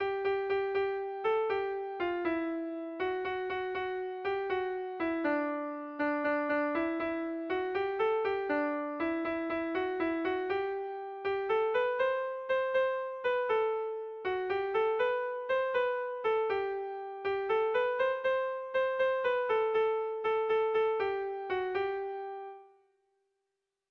Tragikoa